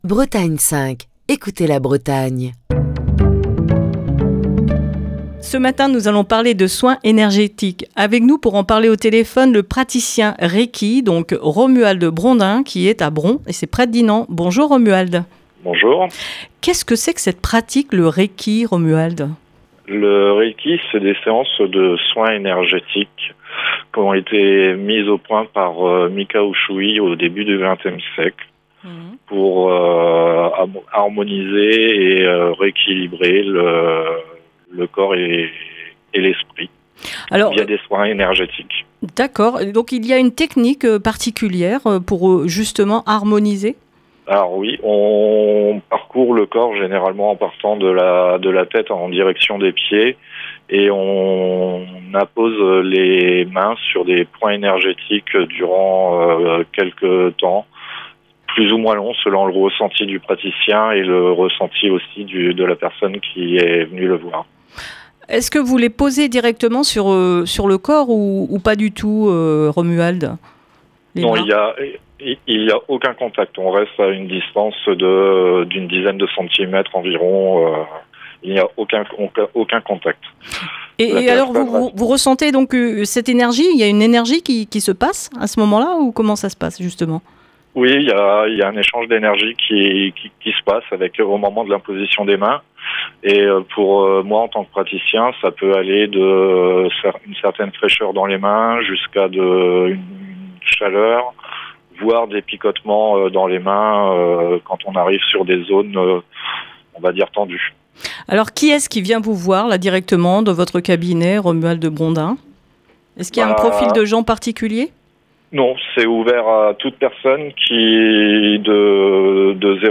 dans le coup de fil du matin